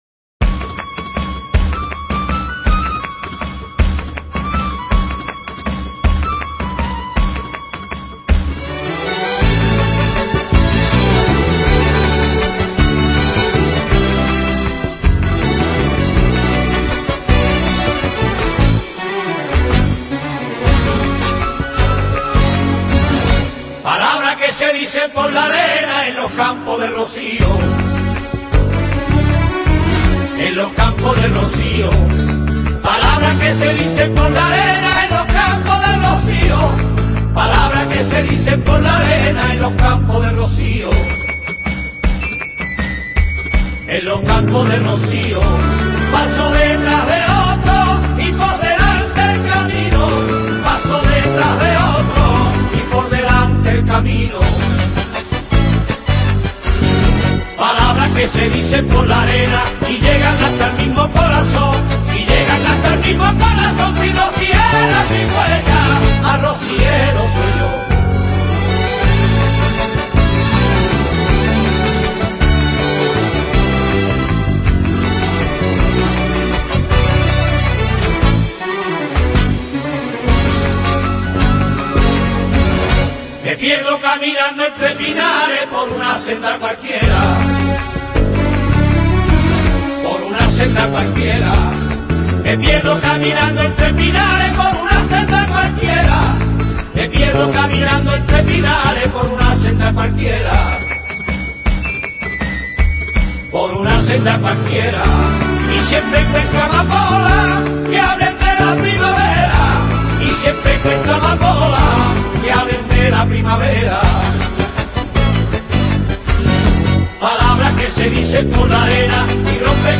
Temática: Rociera